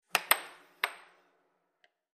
Air Hockey; Puck Hits And Bounce On Table.